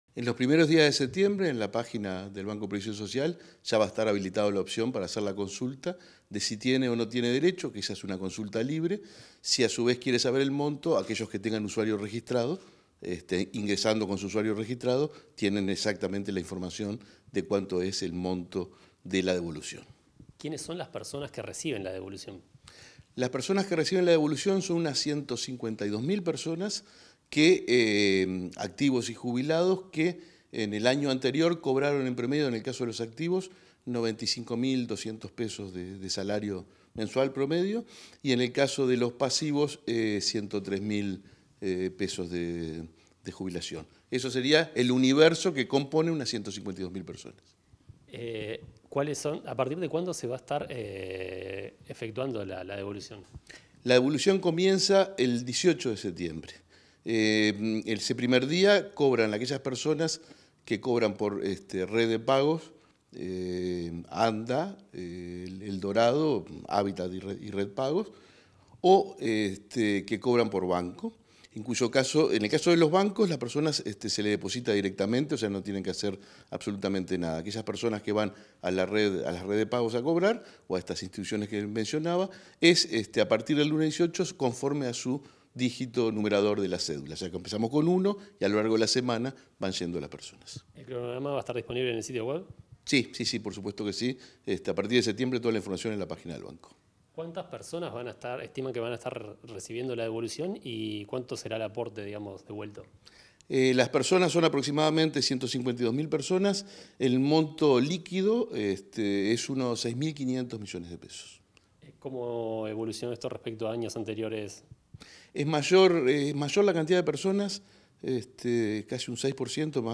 Entrevista al presidente del Banco de Previsión Social (BPS), Alfredo Cabrera